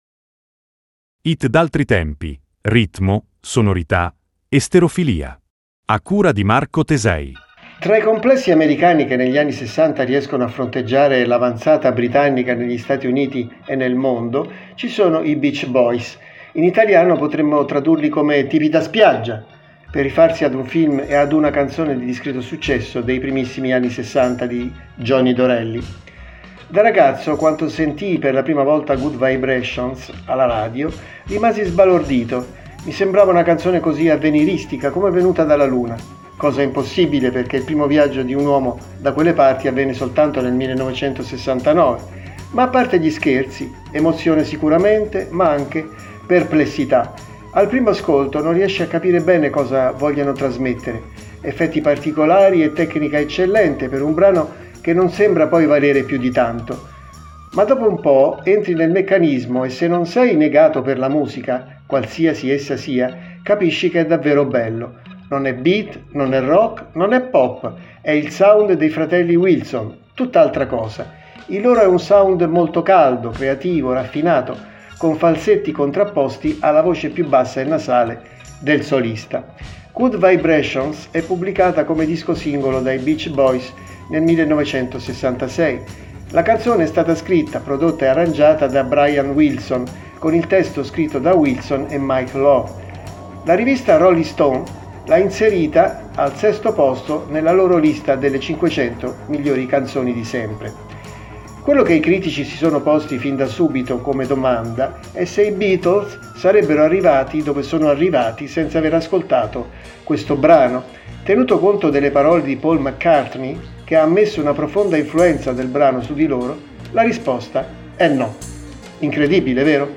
Non è beat, non è rock, non è pop.